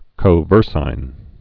(kō-vûrsīn)